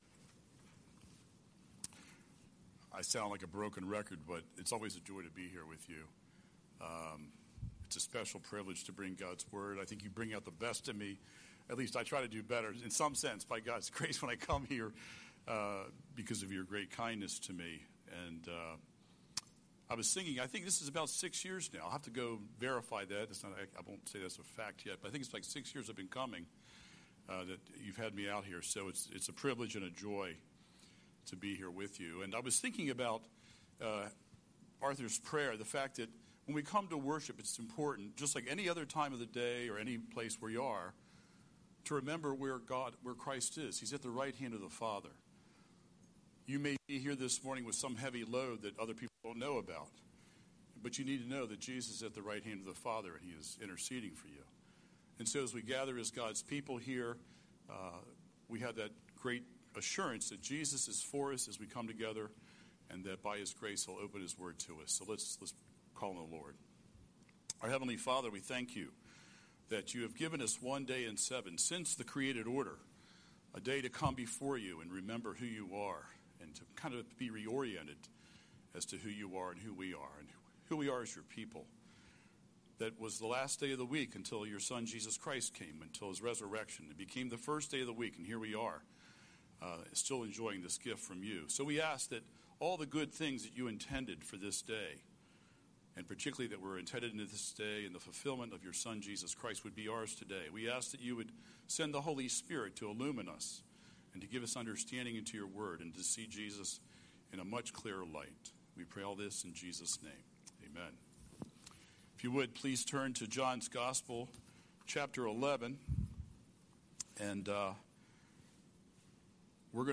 Scripture: John 11:28–44 Series: Sunday Sermon